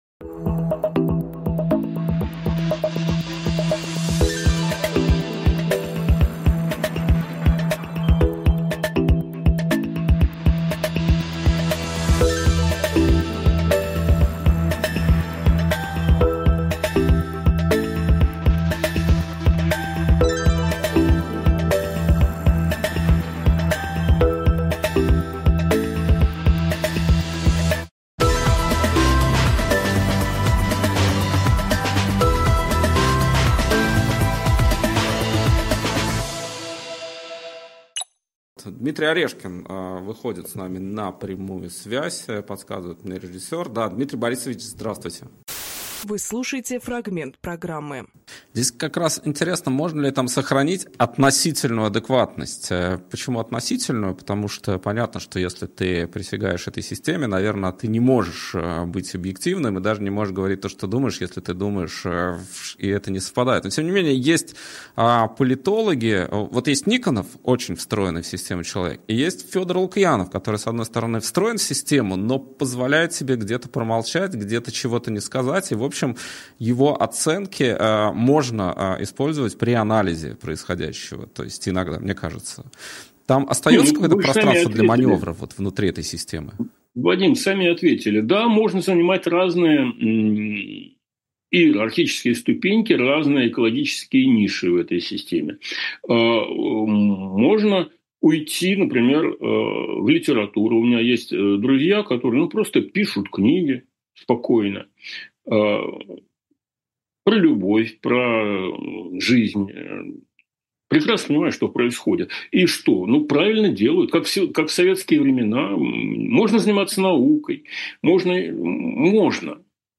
Фрагмент эфира от 05.11.24
Интервью на канале «И грянул Грэм»7 ноября 2024